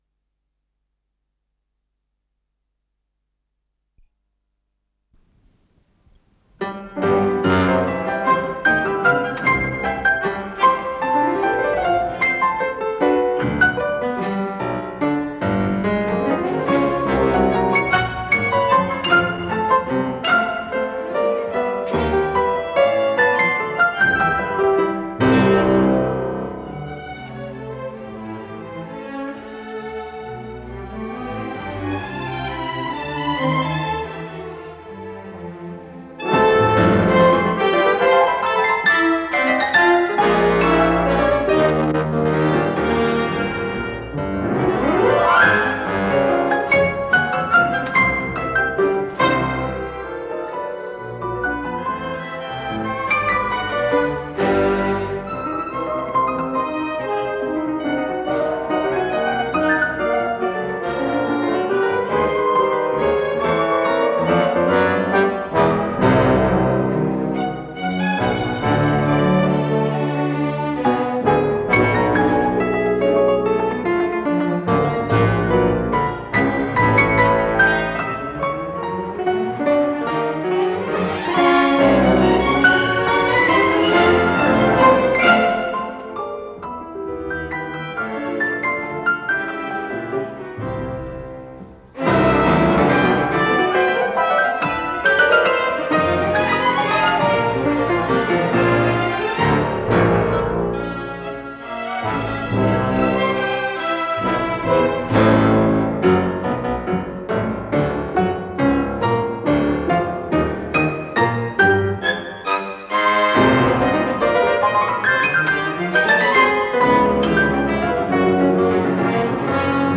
с профессиональной магнитной ленты
ПодзаголовокСоль мажор
ИсполнителиСвятослав Рихтер - фортепиано
АккомпаниментЛондонский симфонический оркестр
Дирижёр - Лорин Маазель
ВариантДубль моно